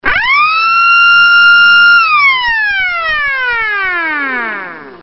SIRENAS ELECTROMECÁNICAS FIBRA
104dB - 1400Hz